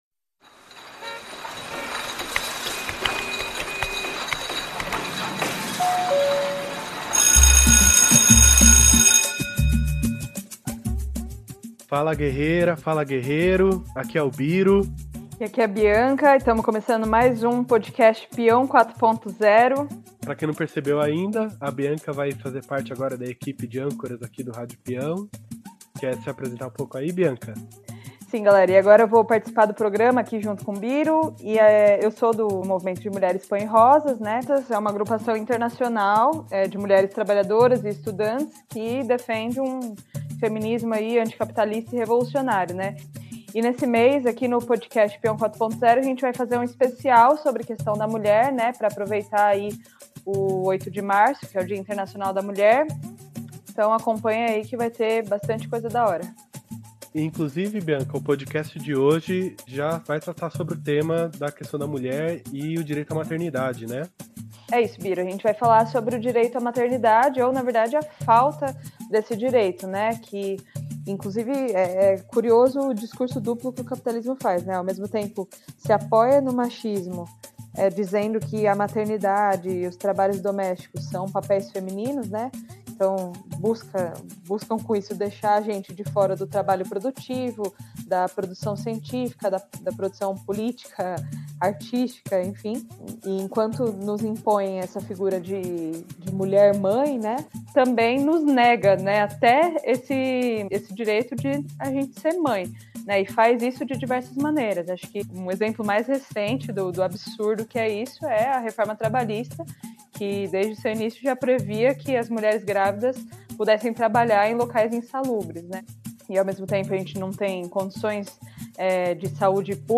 Season 3 Episode 6 · Mar 05, 2020, 03:10 AM Headliner Embed Embed code See more options Share Facebook X Subscribe O episódio nº6 do Rádio Peão 4.0 abre uma série sobre a mulher trabalhadora que será tema neste mês de março e traz trabalhadoras de SP e MG, do Metrô, da saúde e da educação, que falam da realidade das mães que não podem contar com as creches noturnas quando trabalham neste período e também comentam as contradições em torno do Projeto de Lei 98/2019, que foi proposto pelo PSC e estende o horário das creches até o período noturno.